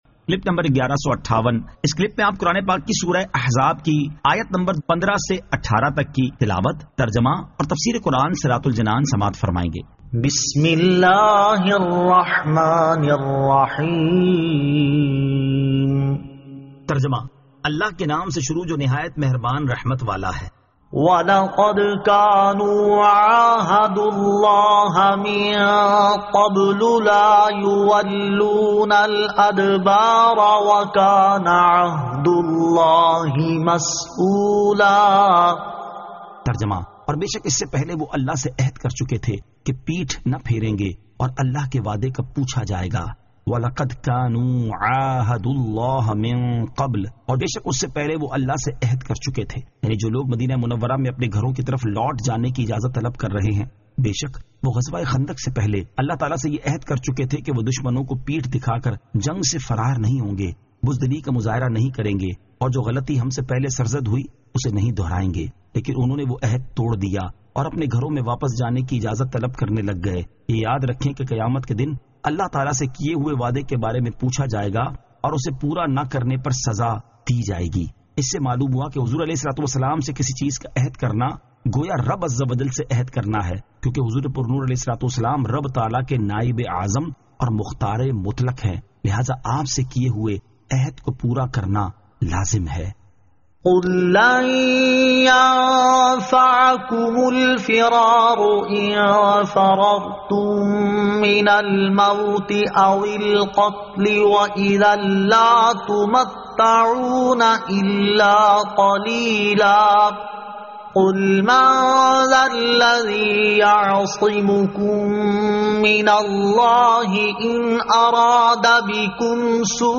Surah Al-Ahzab 15 To 18 Tilawat , Tarjama , Tafseer